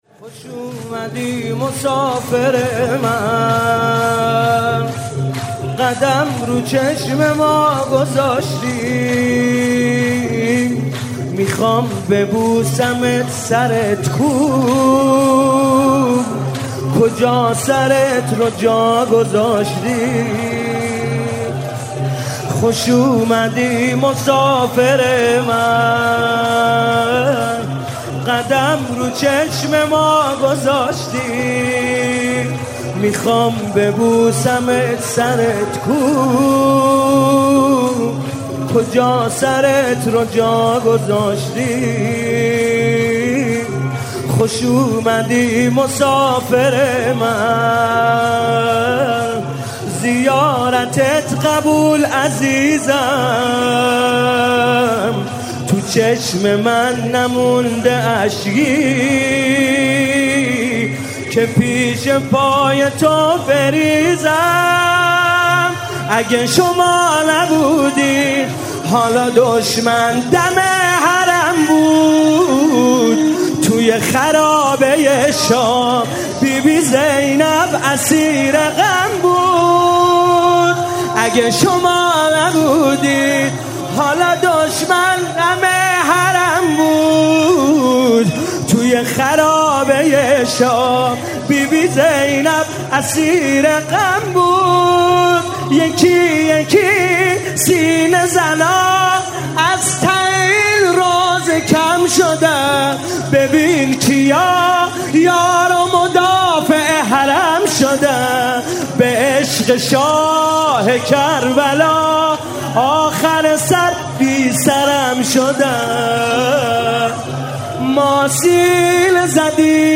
شب چهارم محرم - دوطفلان حضرت زینب سلام الله علیها
محرم 97 شب چهارم - حسین طاهری - شور - خوش اومدی مسافر من
حسین طاهری